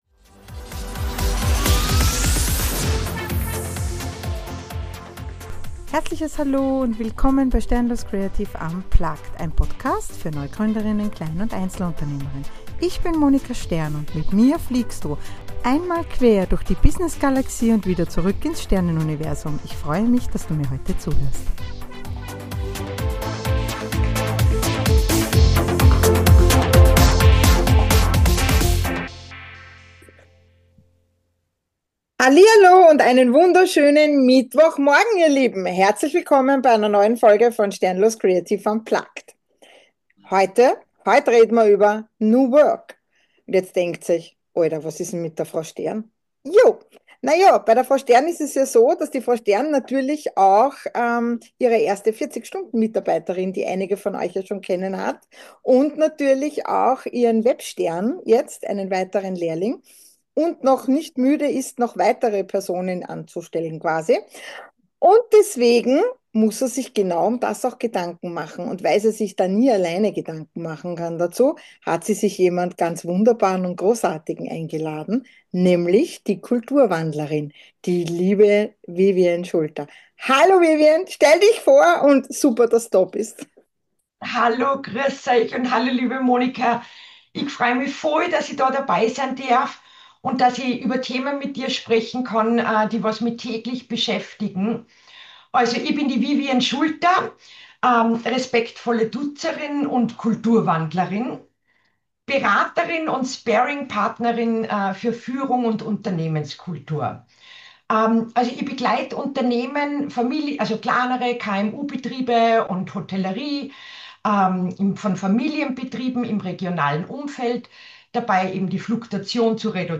INTERVIEW
Ein ehrlicher, humorvoller und praxisnaher Austausch – für dich als Gründerin, Unternehmerin oder wenn du Arbeit menschlicher gestalten möchtest.